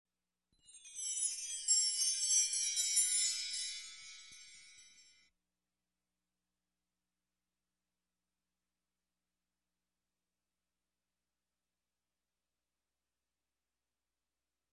Перезвон колокольчиков — волшебные звуки
Звук появления феи